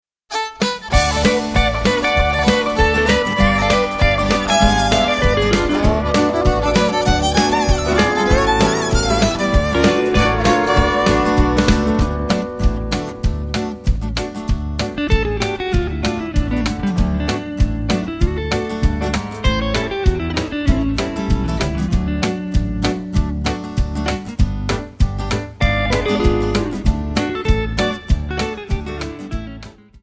Bb
Backing track Karaoke
Country, 1990s